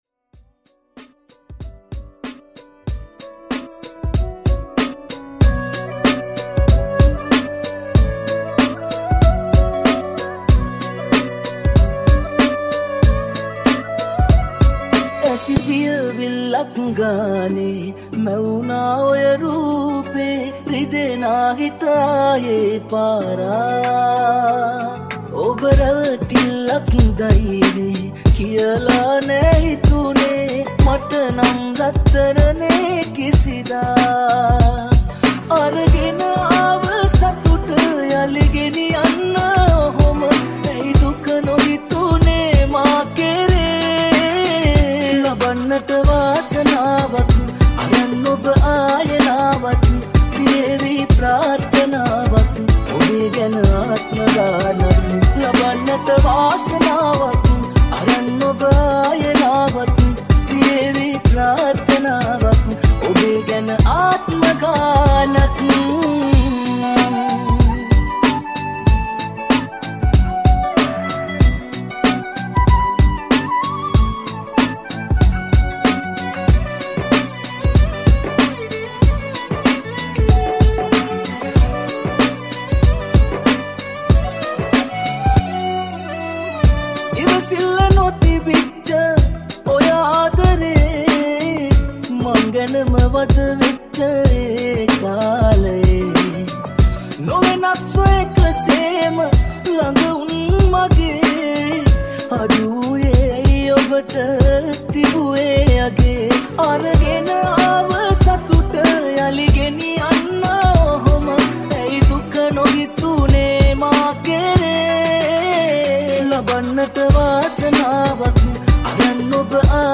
BPM 94